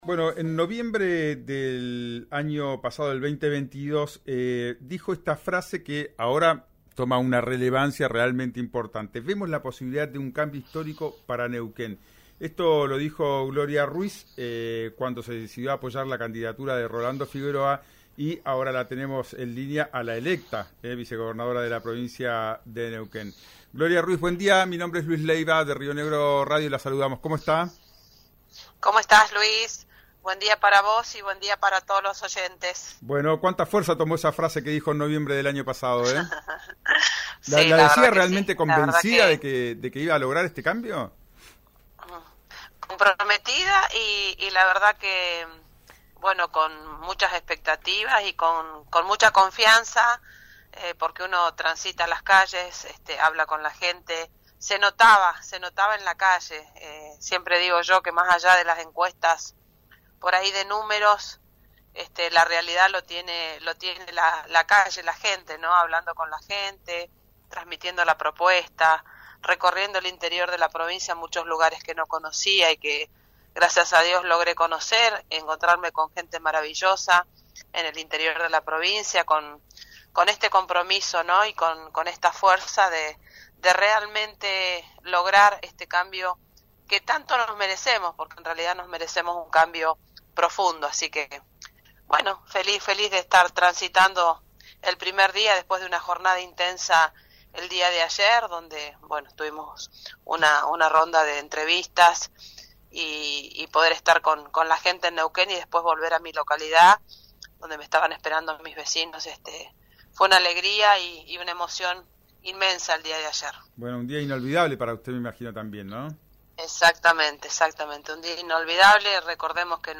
Escuchá en RÍO NEGRO RADIO a la intendenta de Plotier, vicegobernadora electa que estuvo en la lista junto a Rolando Figueroa.